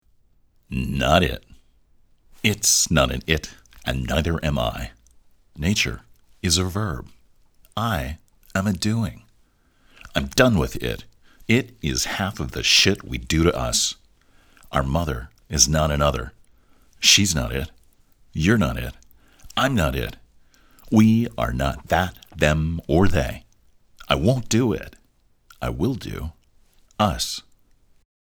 Listen to me read.